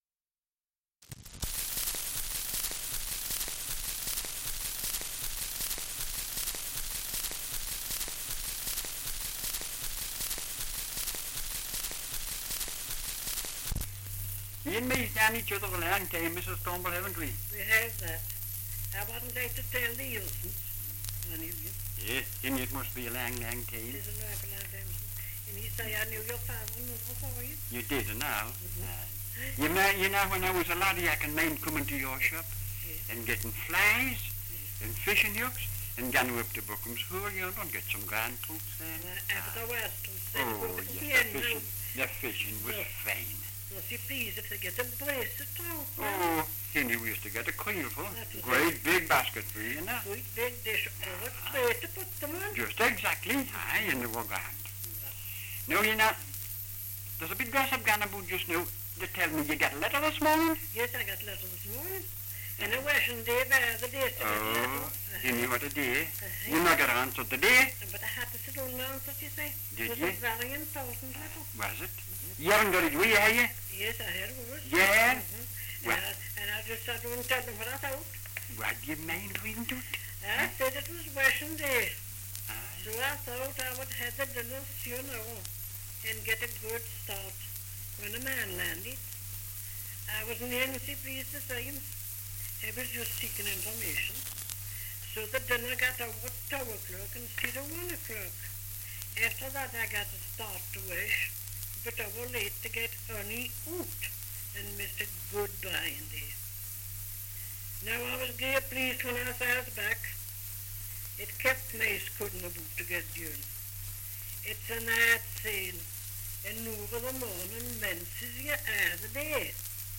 Dialect recording in Rothbury, Northumberland
78 r.p.m., cellulose nitrate on aluminium